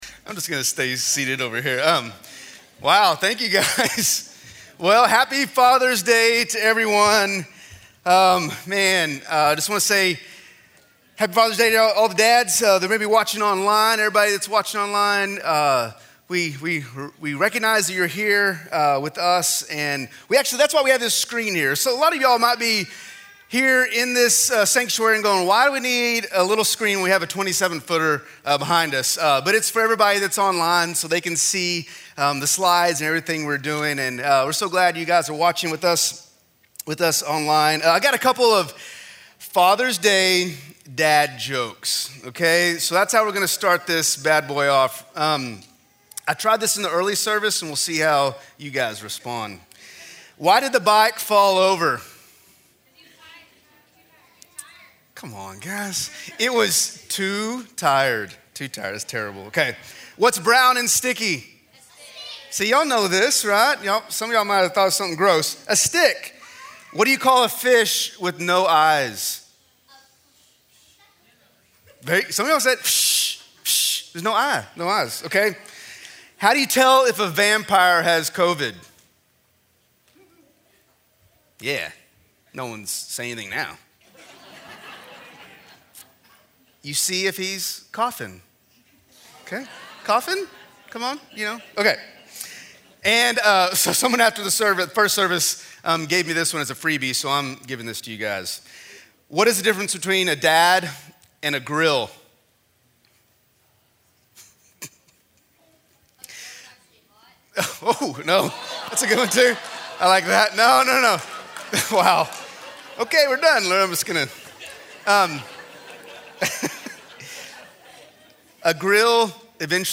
A message from the series "Our Imperfect Family." We are kicking off our new series "Our Imperfect Family" today!